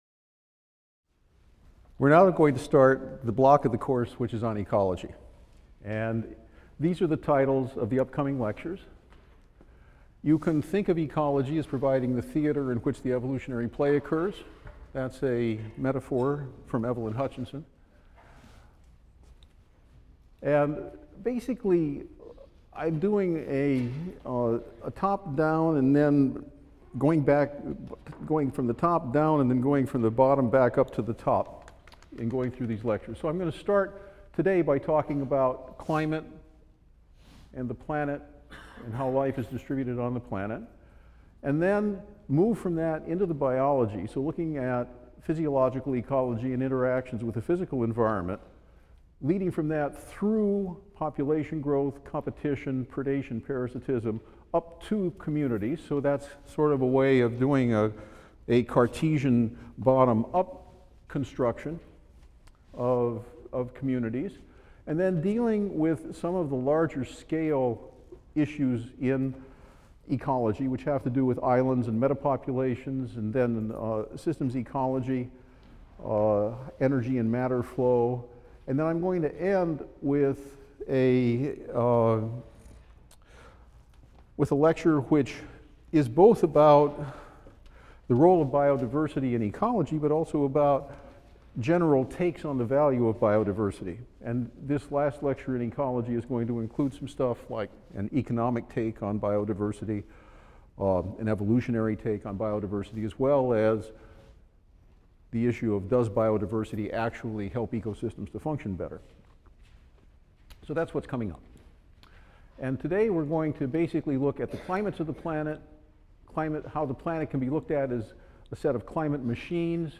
E&EB 122 - Lecture 24 - Climate and the Distribution of Life on Earth | Open Yale Courses